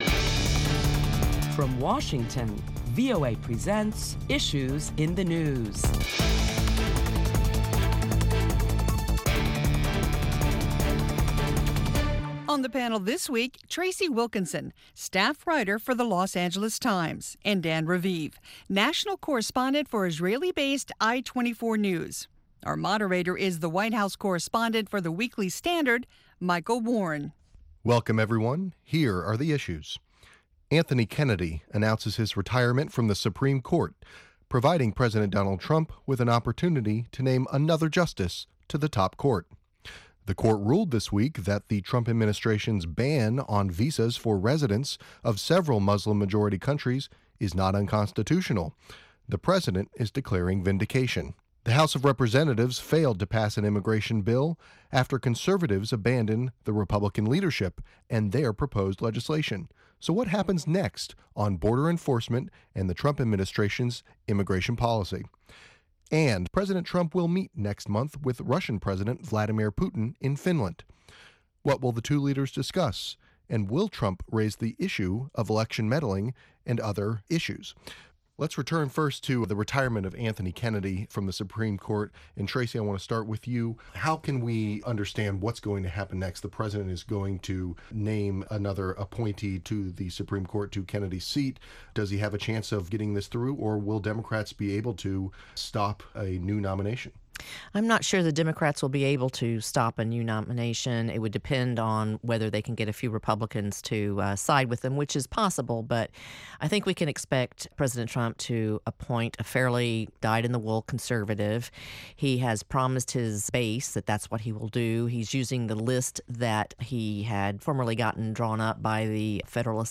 round table discussion